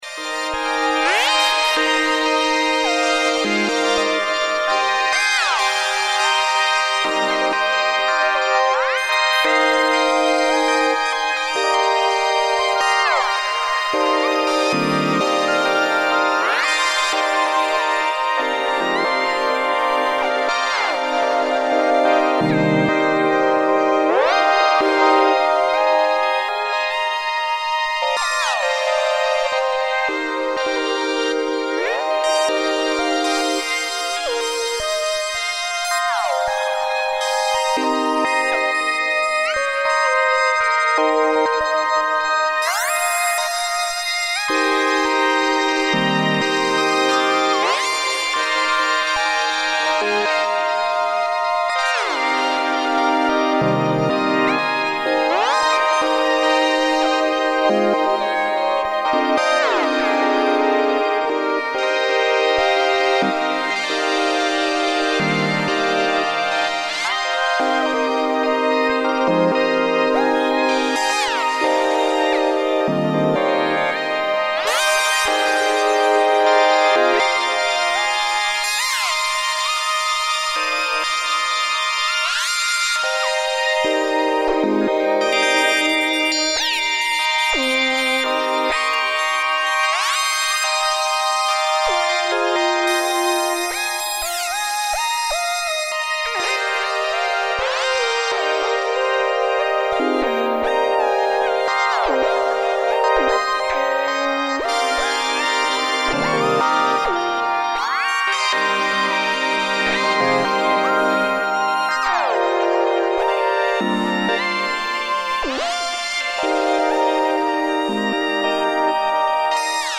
EDMニューエイジ激しい